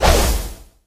gus_atk_01.ogg